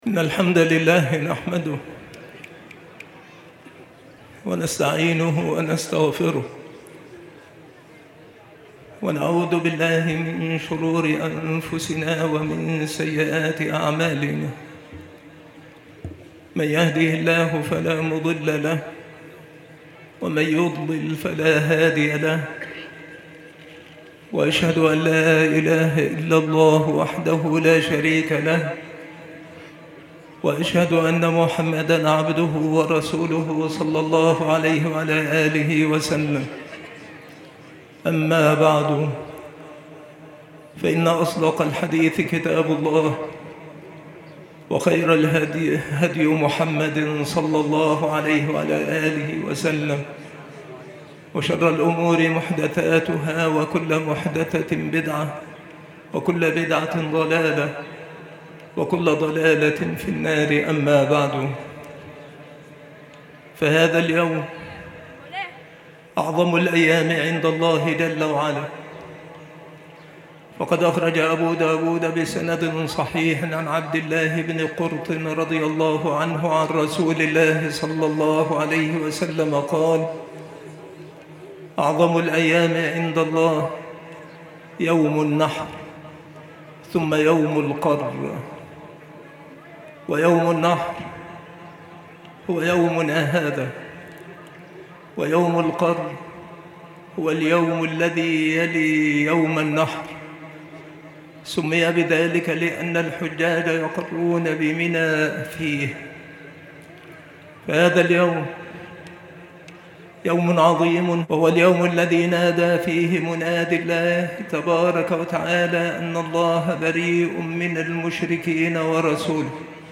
خطبة عيد الأضحى لعام 1446هـ
خطب العيدين
مكان إلقاء هذه المحاضرة بسبك الأحد - أشمون - محافظة المنوفية - مصر